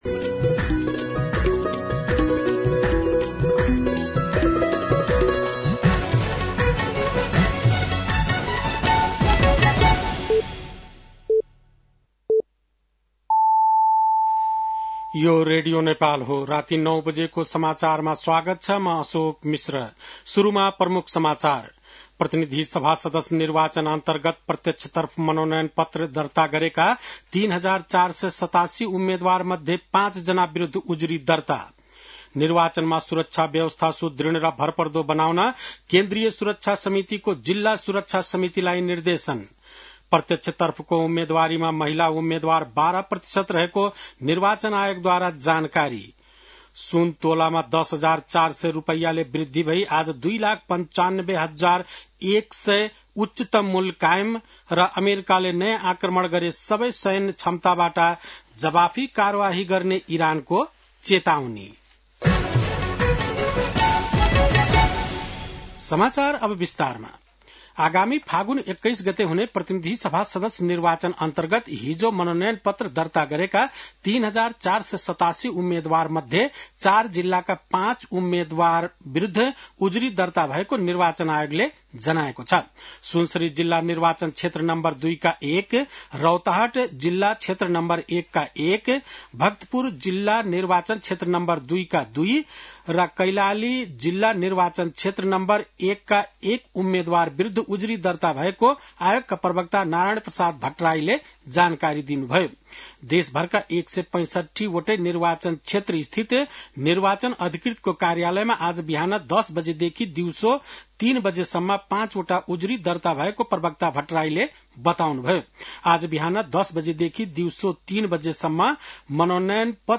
बेलुकी ९ बजेको नेपाली समाचार : ७ माघ , २०८२